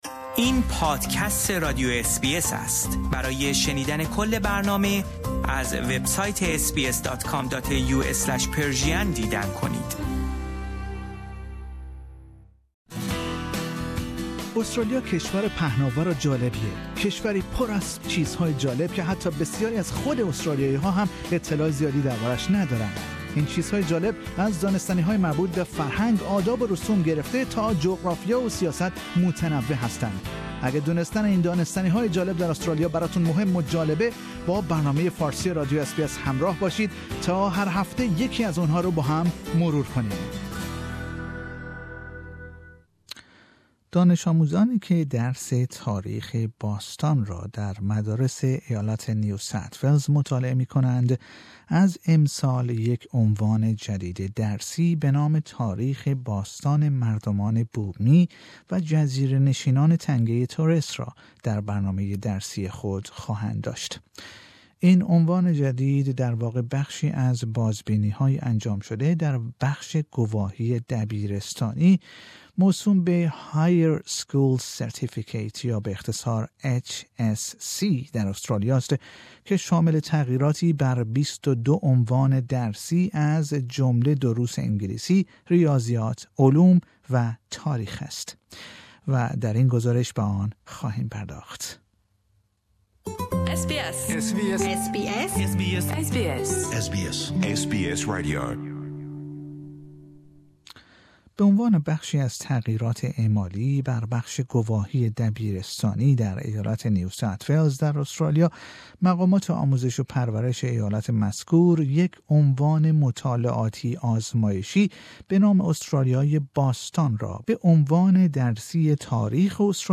و در این گزارش به آن خواهیم پرداخت.